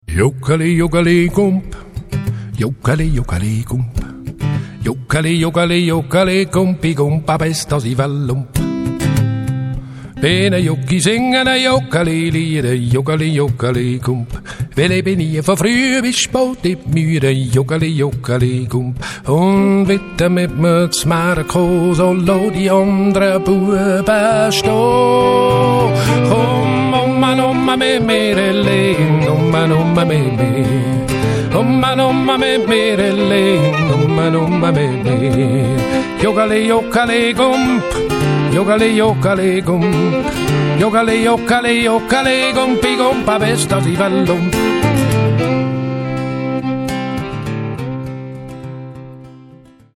Eurostudio Wildenstein, Bubendorf / Schweiz.